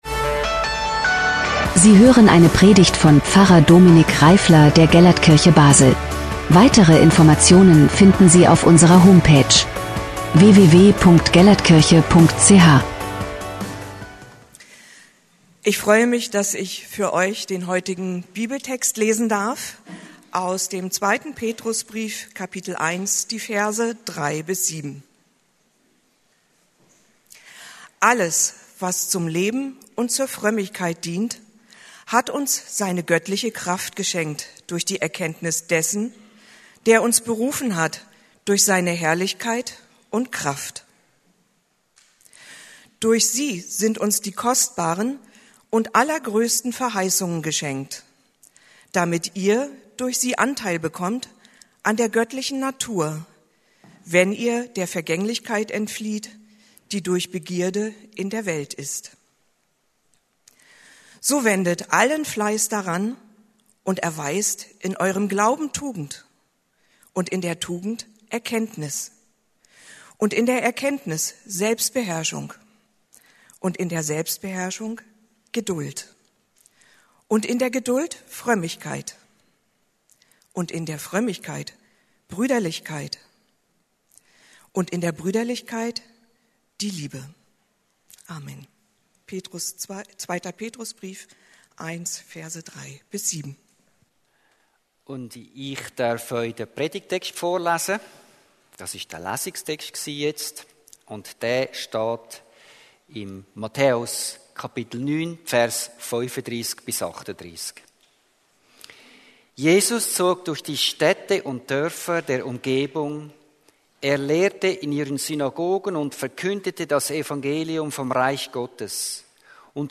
Letzte Predigt